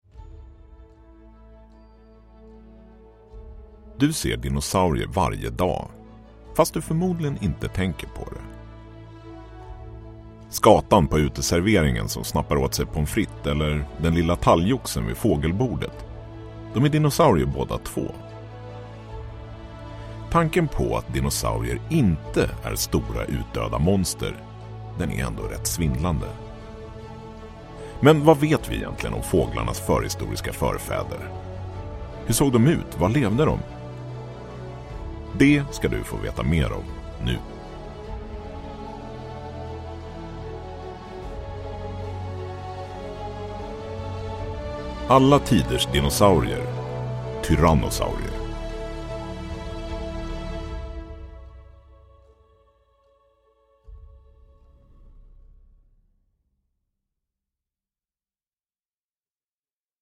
Alla tiders dinosaurier 3 - Tyrannosaurus – Ljudbok – Laddas ner